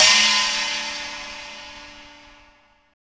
CYM CHINA 03.wav